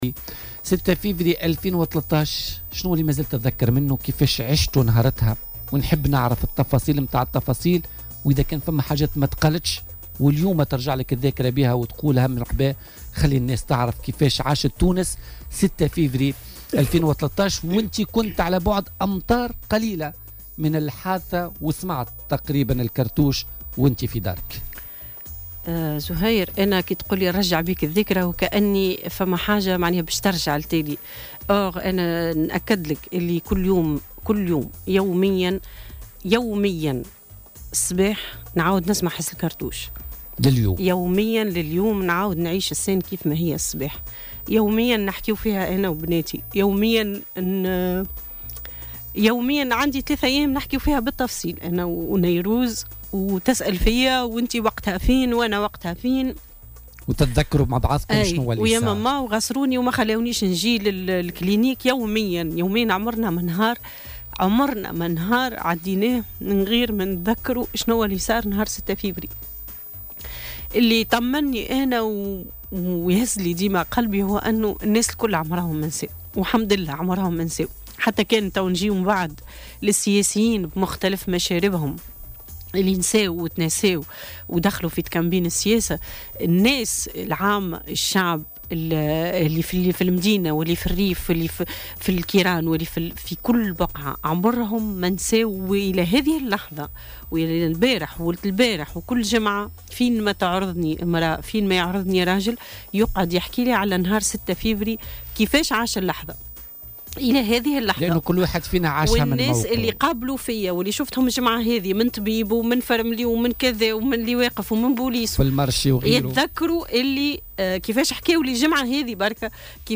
وأكدت بسمة بلعيد، رئيسة منظمة شكري بلعيد ضد العنف وضيفة "بوليتيكا" على "الجوهرة أف أم" عشية احياء ذكرى اغتيال شكري بلعيد، أنها الى غاية اللحظة تستذكر هذا التاريخ بكل تفاصيله الأليمة التي يذكرها كل التونسيين باستثناء البعض القليل منهم.